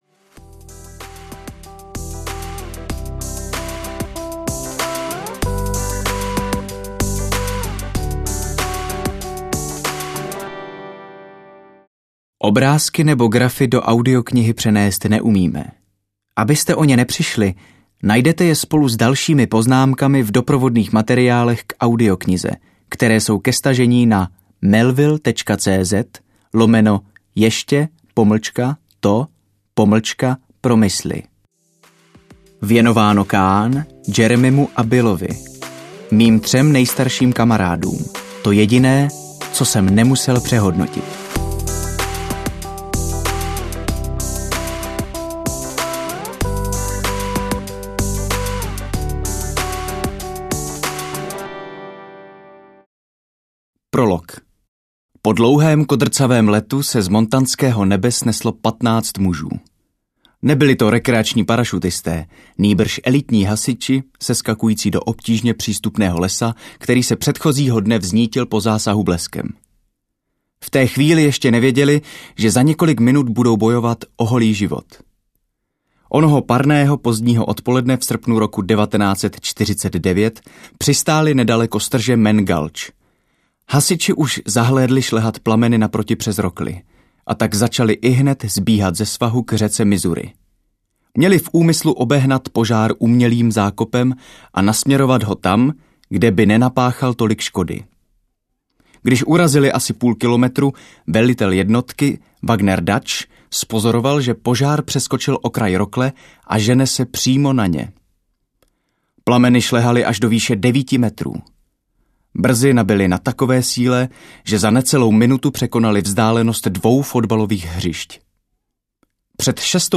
Ještě to promysli audiokniha
Ukázka z knihy